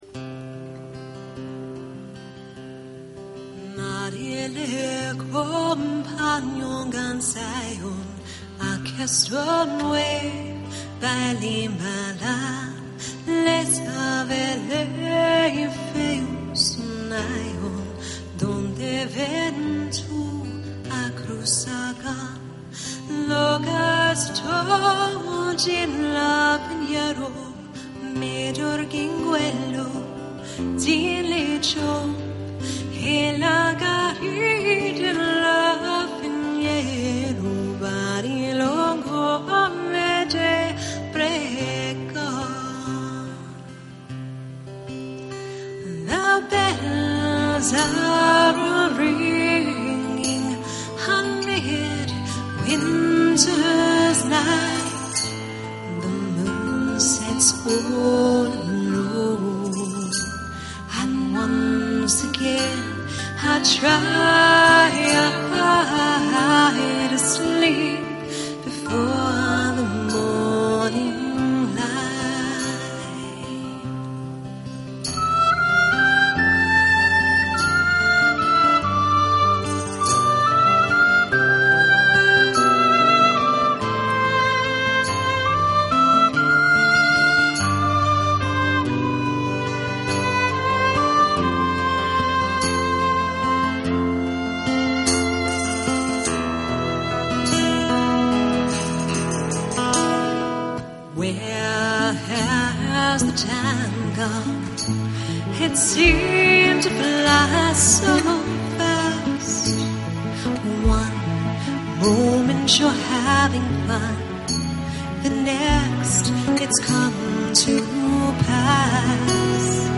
【民謠搖滾樂隊】
現場實錄
◎ 用原聲木吉他演奏醉人旋律，搭配不染塵囂的嗓音的民謠搖滾樂隊
◎ 以多元樂器完美詮釋World music、New Age、Medieval Folk…等曲風
◎ 【CD】收錄樂團英國巡迴實況